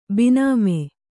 ♪ bināme